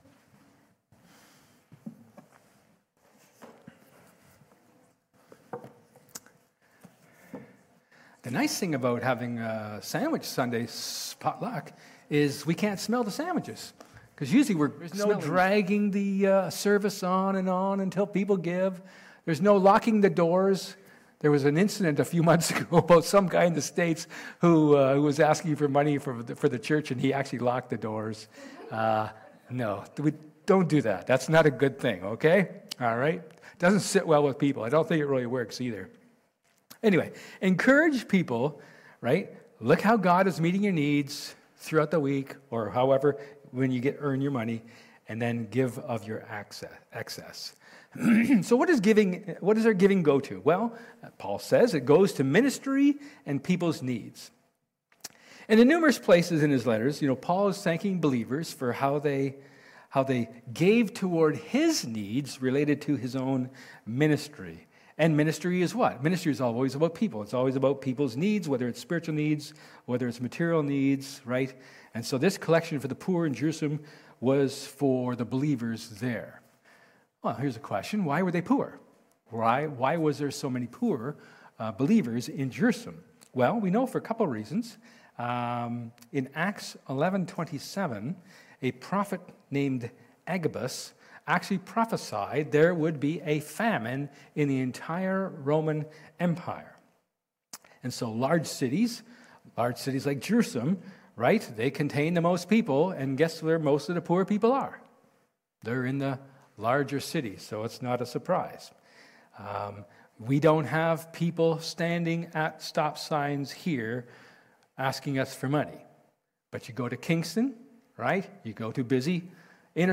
Service Type: Sermon
April-27-sermon.mp3